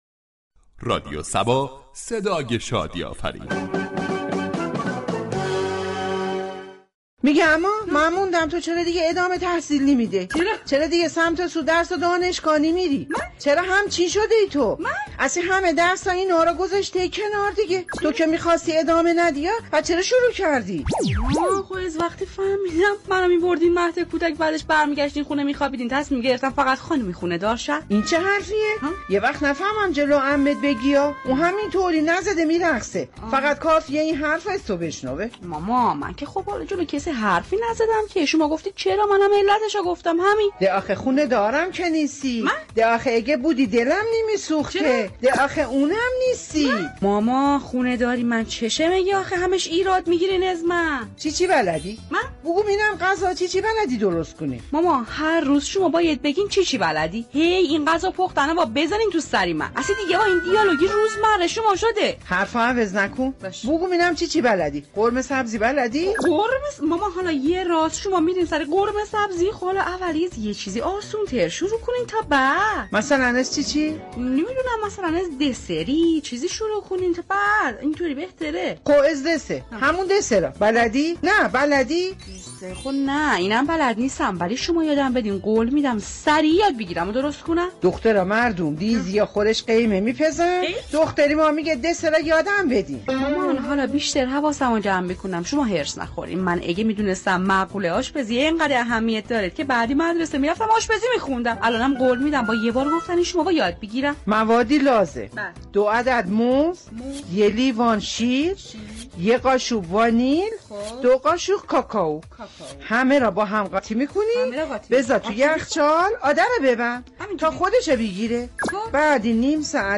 شهر فرنگ در بخش نمایشی با بیان طنز به موضوع دوگانه ادامه تحصیل یا خانه داری پرداخته است ،در ادامه شنونده این بخش باشید.